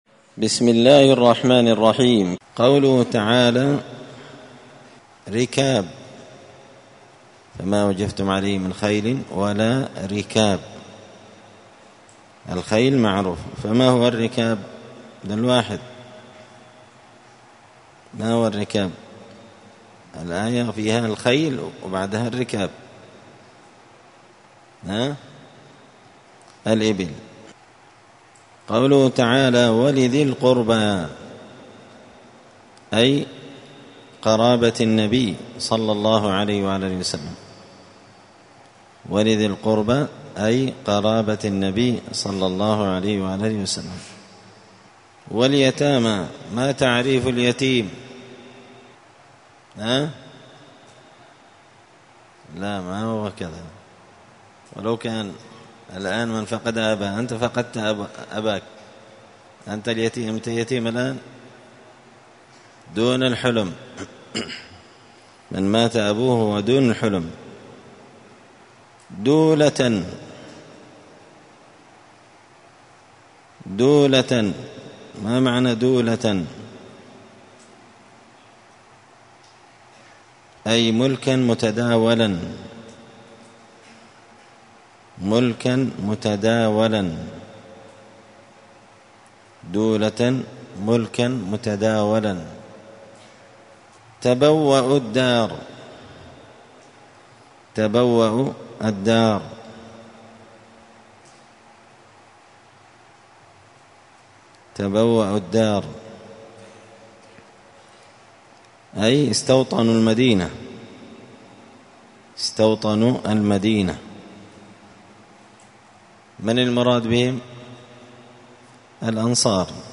مسجد الفرقان قشن_المهرة_اليمن 📌الدروس اليومية